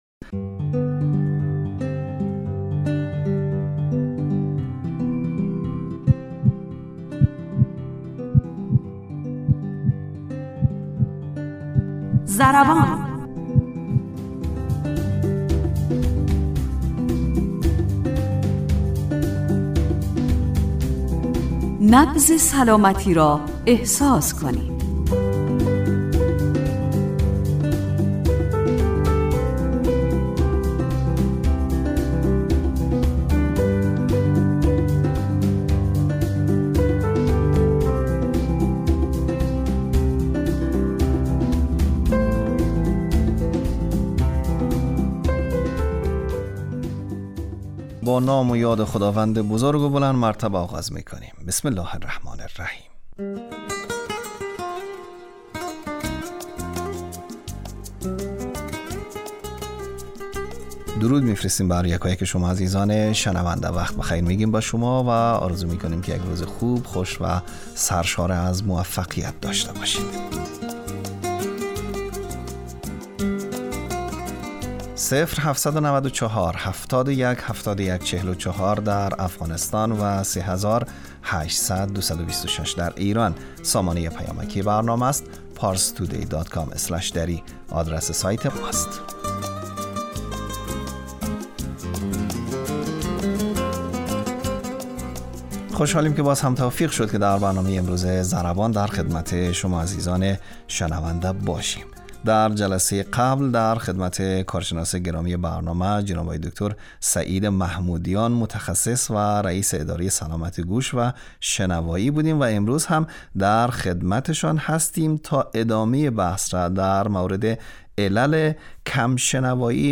برنامه ضربان، برنامه ای صحی و بهداشتی است که با استفاده از تجربیات کارشناسان حوزه بهداشت و سلامت و استادان دانشگاه، سعی دارد مهمترین و شایع ترین مشکلات صحی...